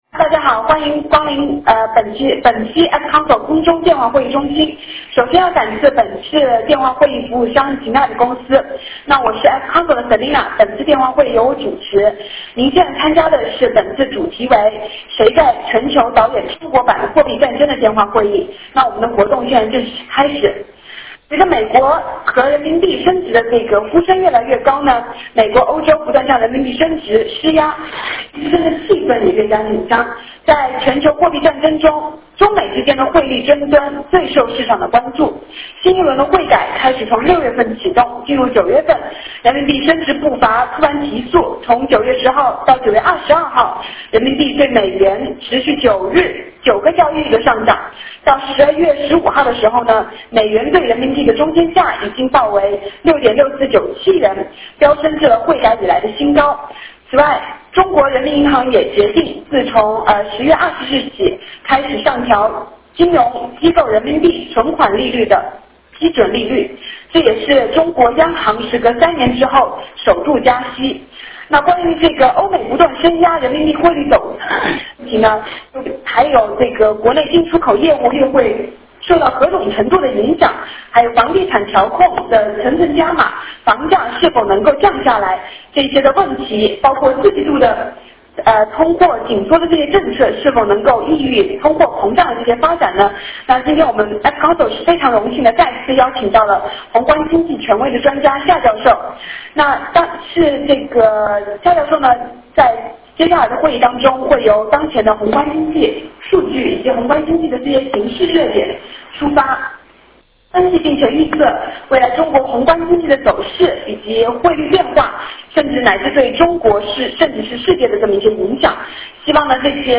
15:00 Q&A 环节